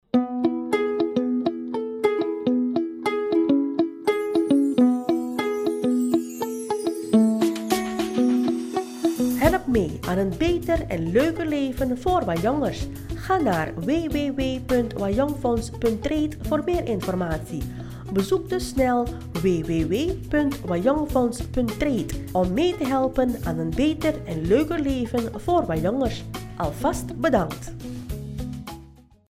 Hieronder kan je luisteren naar de radio-commercial van het WajongFonds.